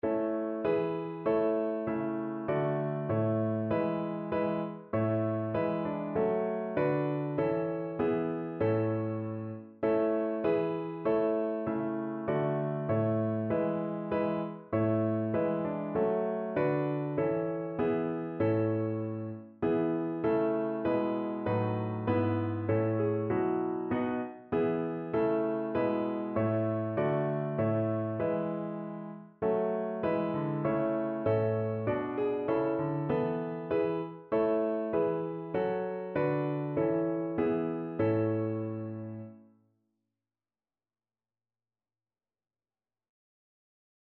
Notensatz 1 (4 Stimmen gemischt)
• gemischter Chor mit Akk.